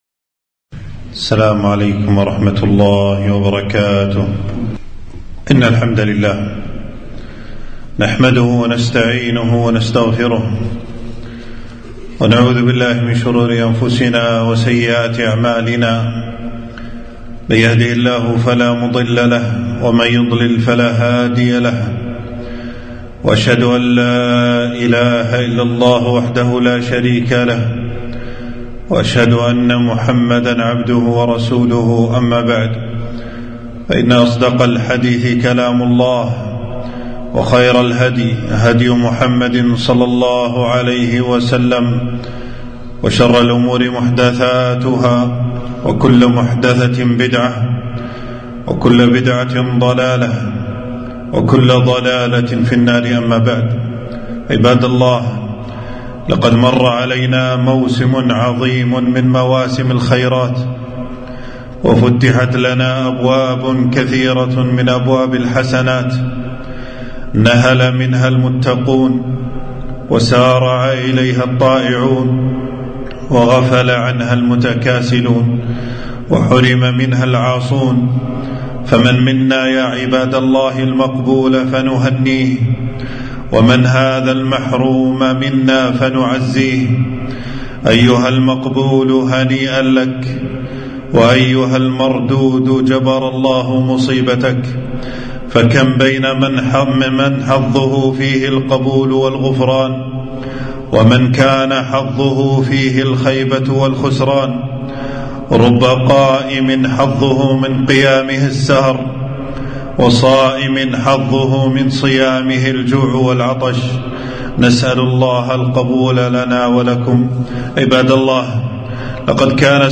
خطبة - الثبات على الإيمان بعد انقضاء رمضان